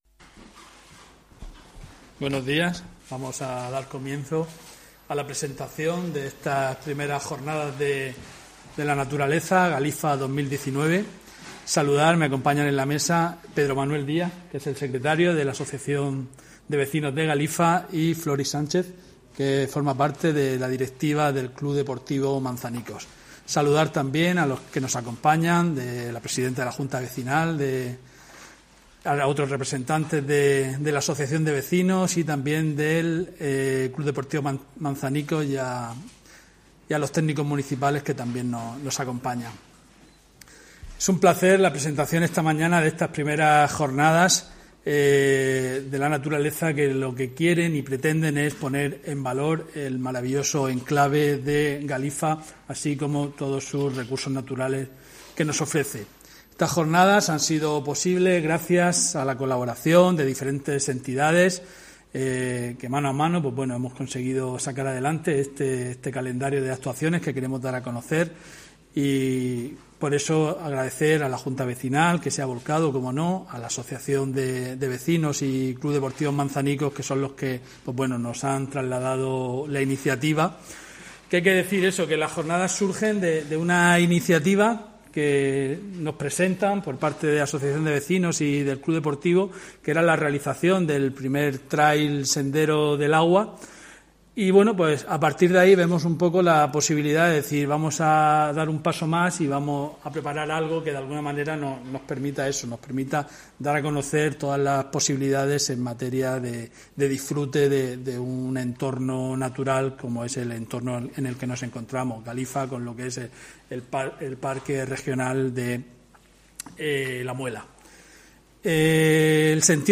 Audio: Presentaci�n I Jornadas Naturaleza Galifa (MP3 - 5,49 MB)